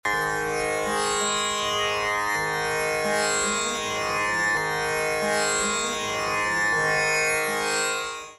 tanpūrā
Utilisée pour produire un bourdon harmonique continu, elle sert essentiellement de base et de support à une mélodie chantée ou jouée par un autre instrument.
C’est un luth à long manche sans frette comportant 4 à 6 cordes.
tampura.mp3